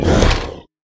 minecraft / sounds / mob / ravager / bite1.ogg
bite1.ogg